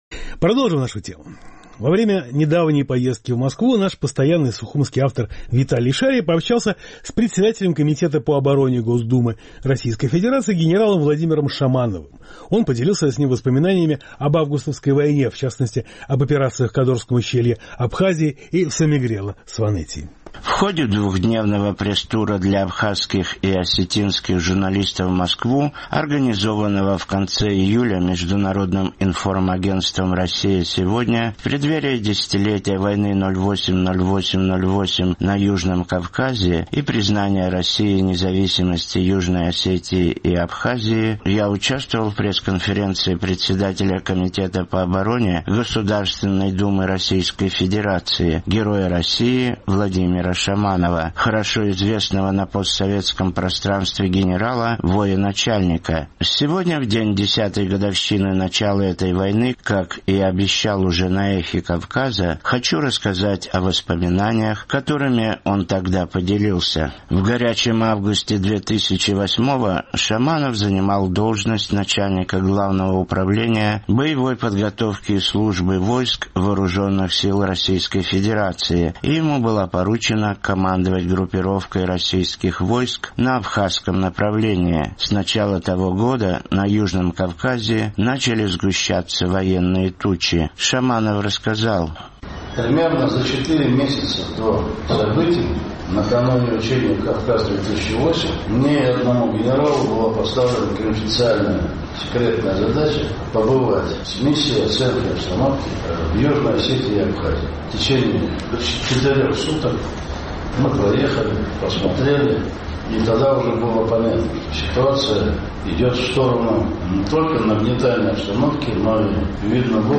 В ходе двухдневного пресс-тура для абхазских и осетинских журналистов в конце июля в Москву состоялась пресс-конференция Героя России Владимира Шаманова.